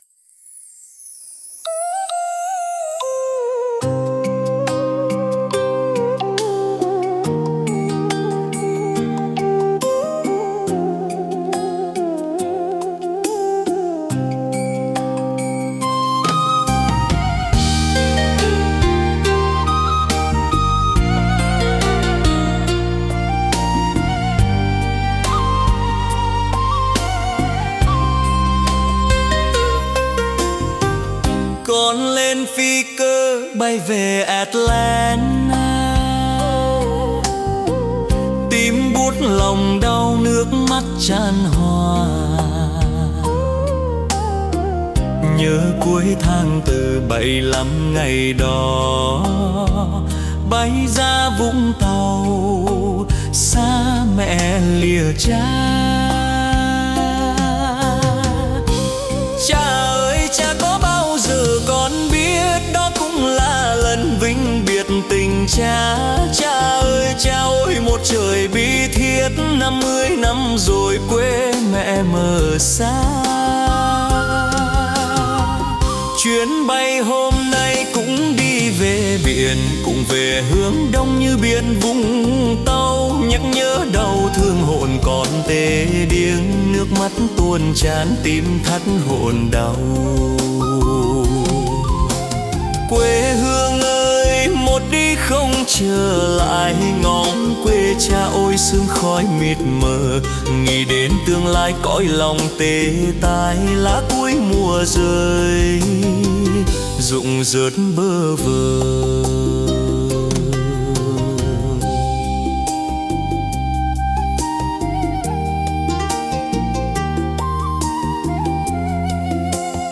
Nhạc Lời Việt